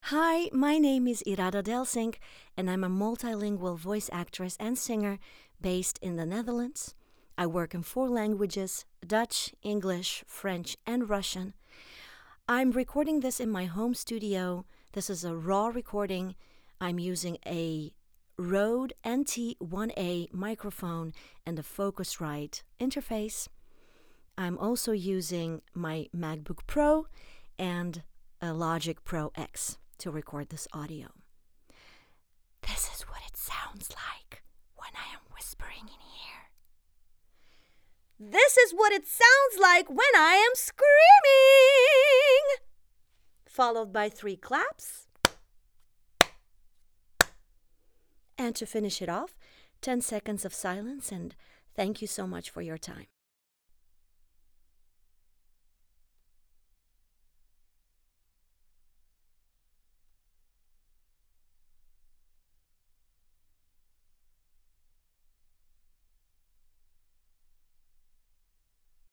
Female
Bright, Bubbly, Character, Children, Confident, Smooth, Warm, Versatile
Transatlantic, East-European, French, Middle-Eastern
Multilingual Reel.mp3
Microphone: Scarlett Solo Studio, AKG Lyra, Rode NT1a
Audio equipment: I have a soundproof studio booth, I record using focusrite, LogicPro X om my MacBook Pro